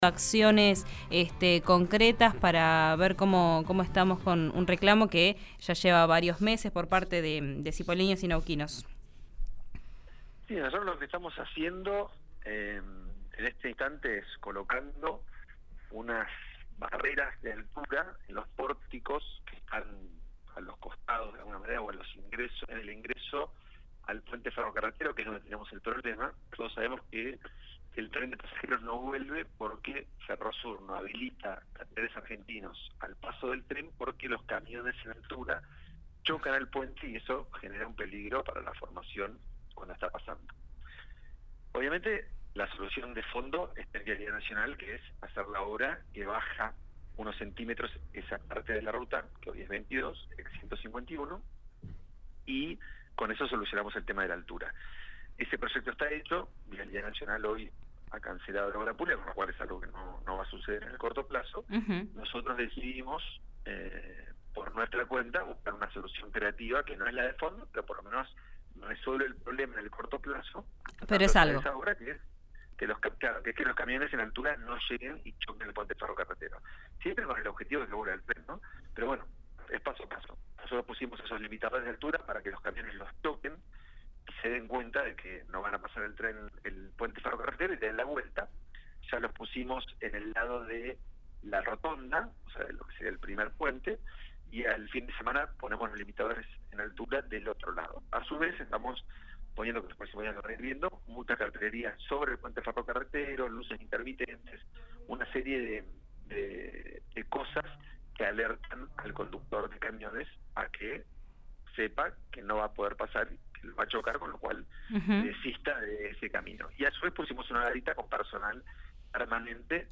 Escuchá a Rodrigo Buteler, intendente de Cipolletti, en RÍO NEGRO RADIO
Durante la entrevista radial, Rodrigo Buteler explicó que el proyecto que tenía como fin bajar la Ruta 151 y evitar las colisiones de los vehículos de gran porte ya estaba aprobado en Vialidad Nacional.